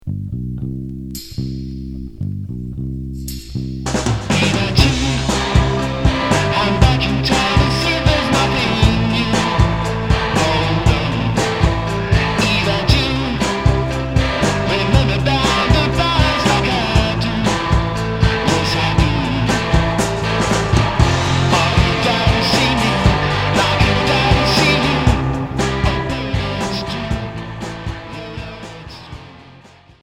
Garage r'n'b Premier 45t retour à l'accueil